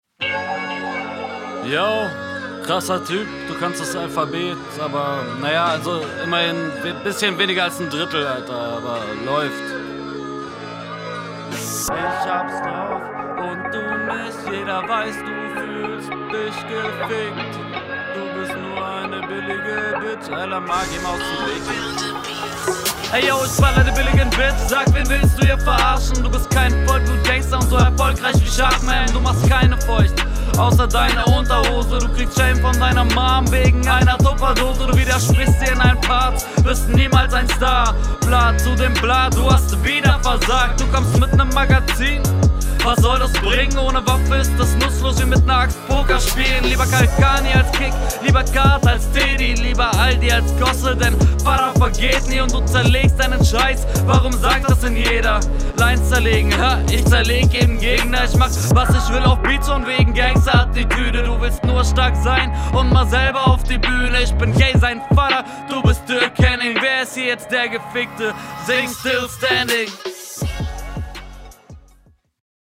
Muss mit viel Fantasie um den Gesang am Anfang zu fühlen.
Gesang tut weh in den Ohren. Abmische aber besser und deutlicher.